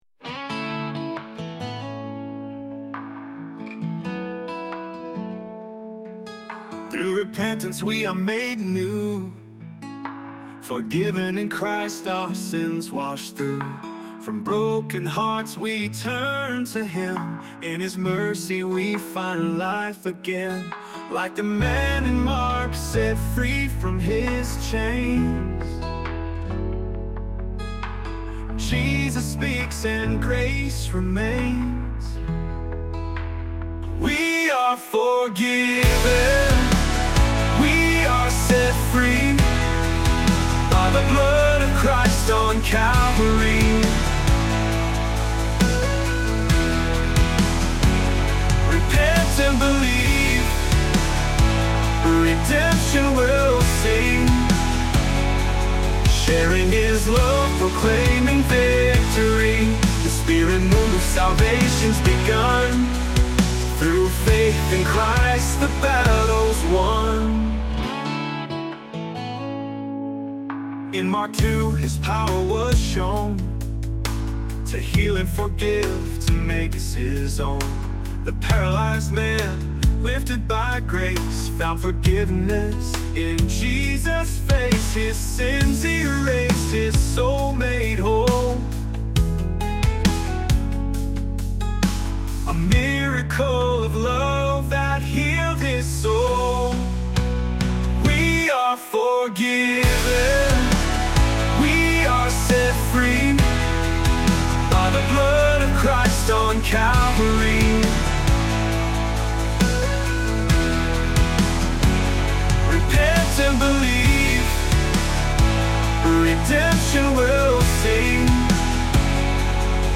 livingStone Sermons
Worship Service at LivingStone Church on August 18, 2024